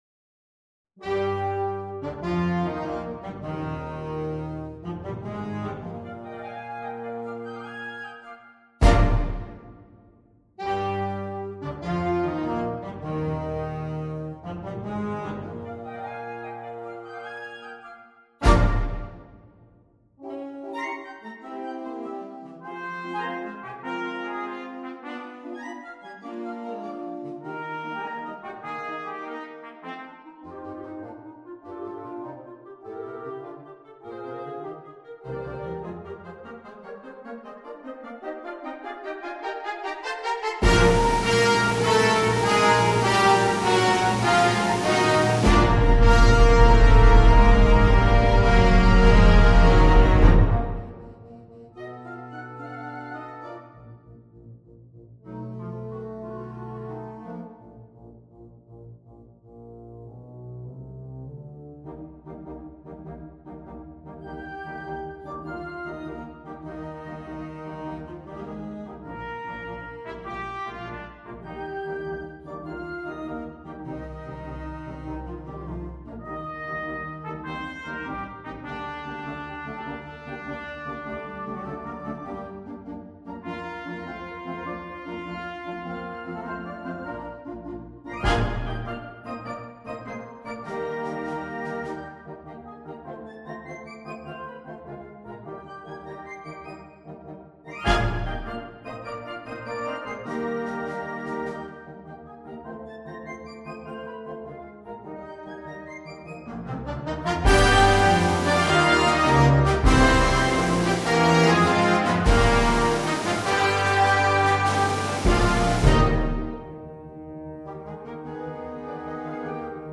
MUSICA PER BANDA
Fantasia sinfonica